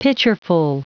Prononciation du mot pitcherful en anglais (fichier audio)
Prononciation du mot : pitcherful